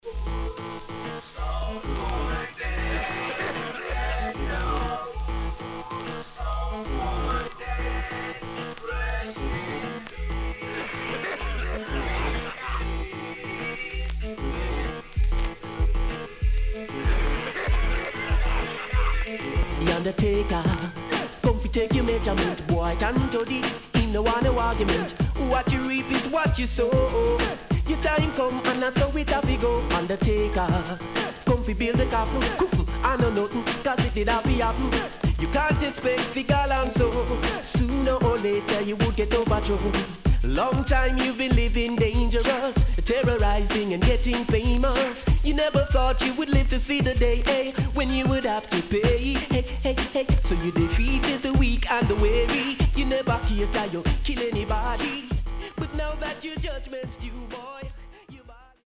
HOME > REISSUE USED [DANCEHALL]
SIDE A:少しチリノイズ入りますが良好です。